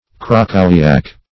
krakowiak - definition of krakowiak - synonyms, pronunciation, spelling from Free Dictionary Search Result for " krakowiak" : The Collaborative International Dictionary of English v.0.48: Krakowiak \Kra*ko"wi*ak\, n. (Mus.) A lively Polish dance.